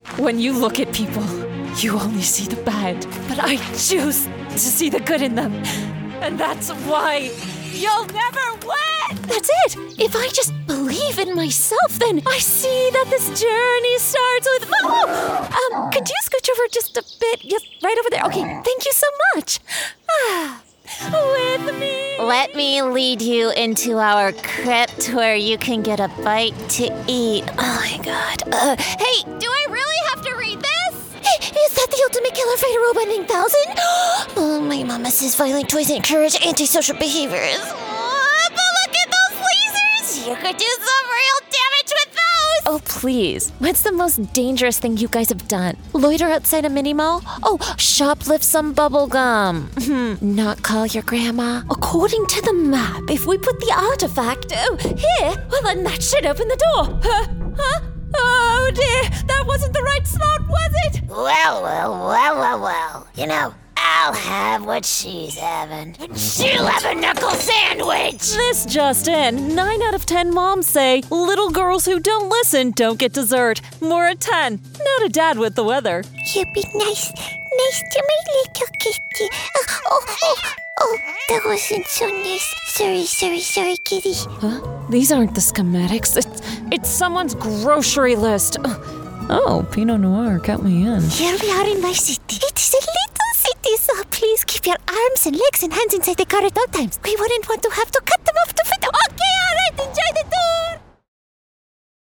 Animation
Rode NT 1, SSL2 Interface, Reaper, Custom Built Studio PC, Sound Booth, Live Direction
Mezzo-SopranoSoprano